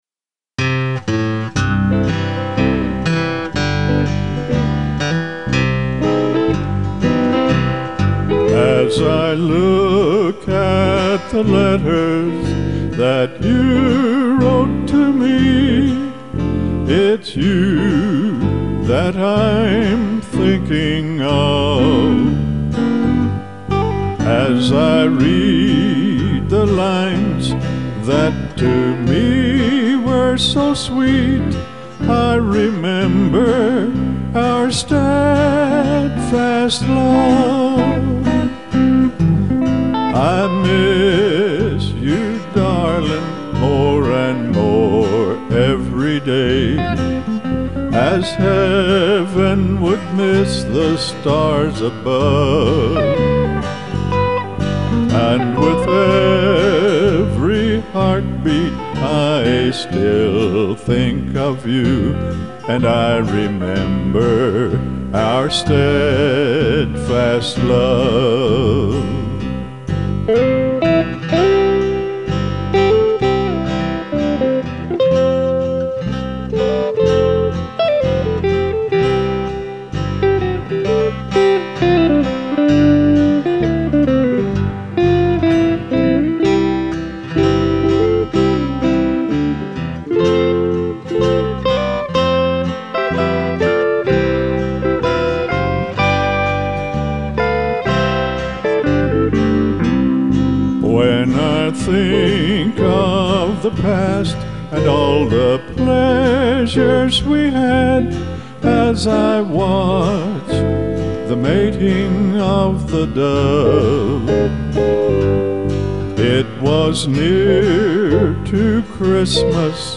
all vocals
all instruments